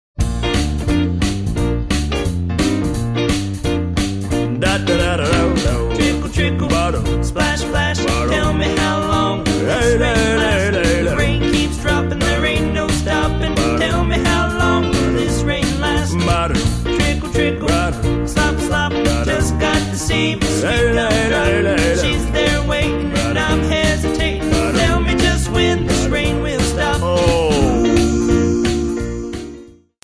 Bb
Backing track Karaoke
Pop, Oldies, 1950s